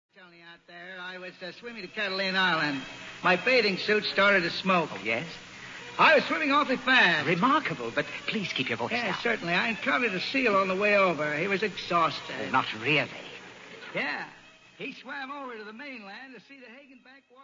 the voice of W.C.Fields